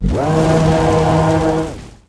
skeledirge_ambient.ogg